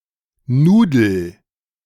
The word for noodles in English was borrowed in the 18th century from the German word Nudel (German: [ˈnuːdl̩]
De-nudel.ogg.mp3